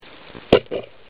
E' stato sufficiente porre una cam con custodia subacqua poggiata sul fondo e sparare col fucile in posizione tale da avere il mulinello molto vicino ( bastano 20 cm ) alla cam.
Di seguito un esempio di onda sonora ( file mp3 e realtiva analisi grafica ) ottenuta con tale sistema:
esempio onda_sparo
Come si può vedere i due suoni ( sgancio grilletto e sagola stirata dal mulinello) sono perfettamente riconoscibili.